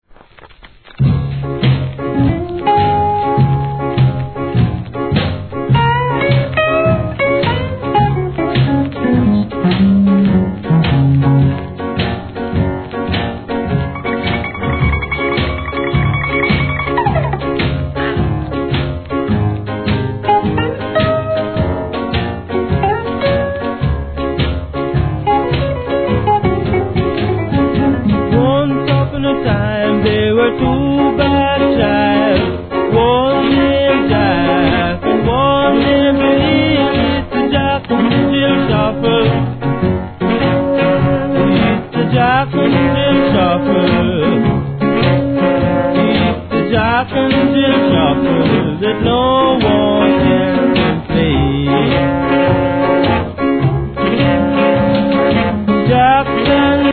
REGGAE
KILLER ROCKSTEADYのINST物!!!